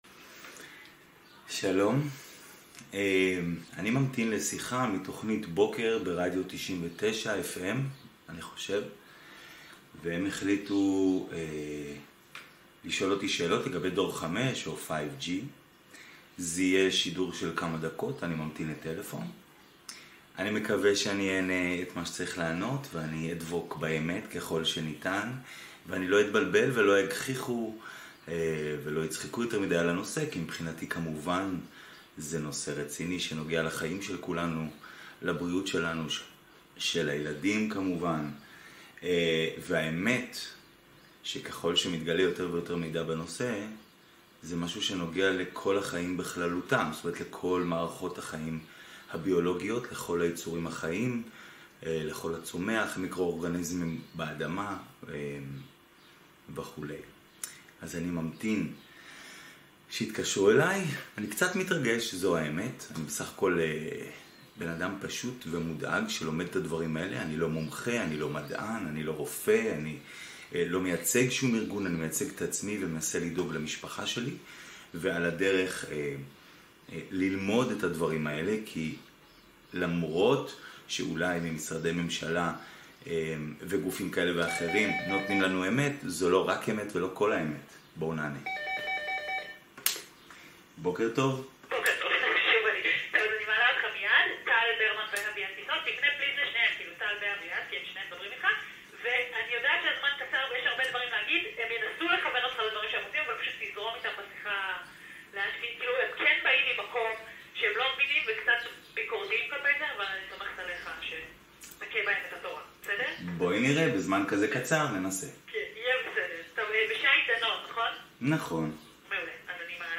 בתוכנית הבוקר ברדיו